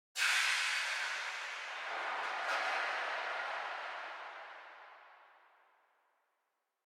ambienturban_17.ogg